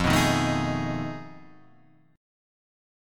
F7#9b5 Chord